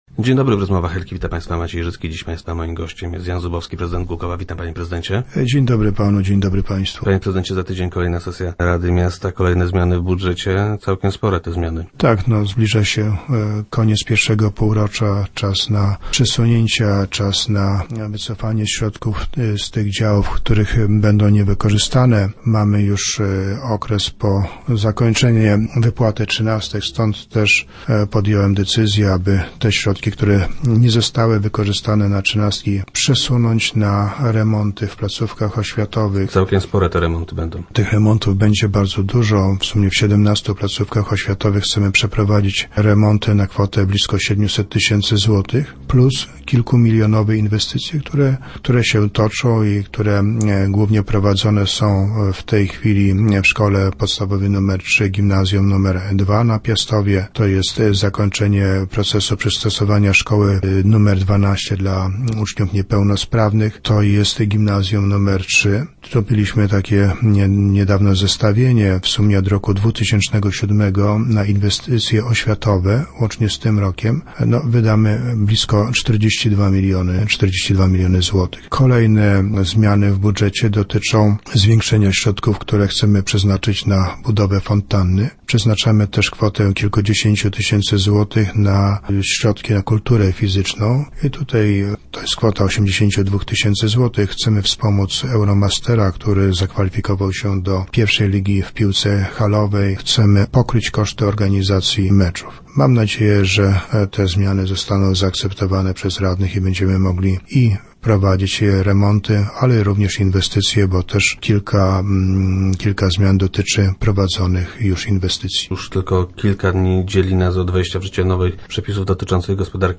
Dodatkowe środki przeznaczone zostaną na głogowską oświatę. - Zamierzamy przeprowadzić kolejne remonty w naszych szkołach i przedszkolach – informuje prezydent Jan Zubowski, który był gościem Rozmów Elki.